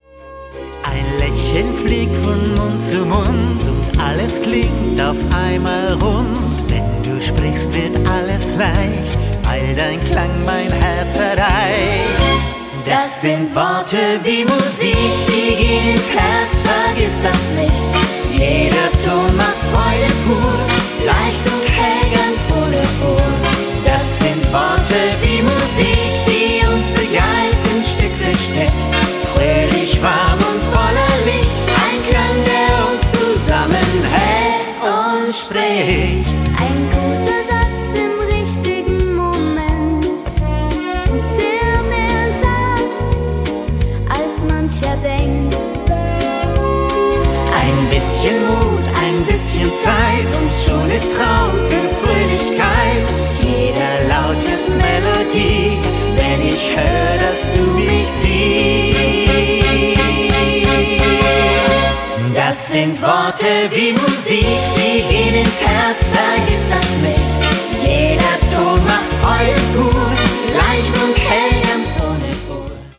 voller Fröhlichkeit und mit einem mitreißenden Swing